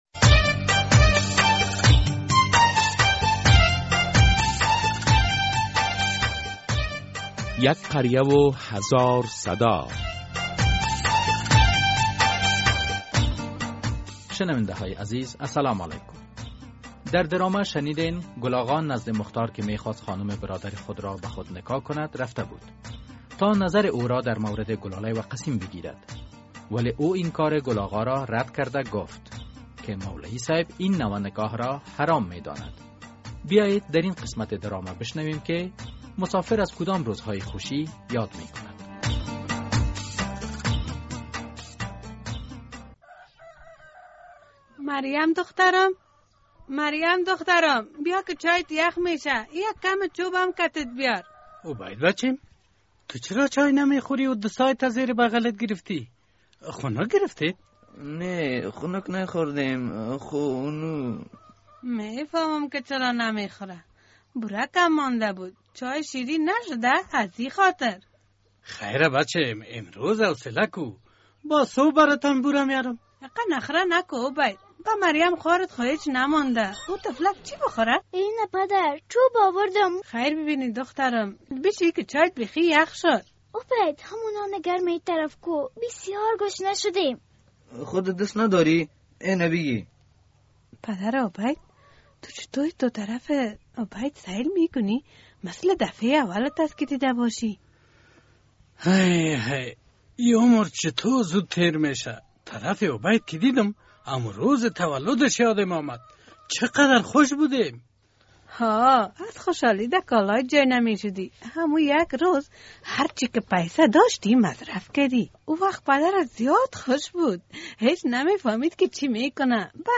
در ۱۸۵مین قسمت درامه یک قریه هزار صدا عکس العمل‌های برخی خانواده‌ها را در زمان تولد دختر و پسر می‌شنوید ...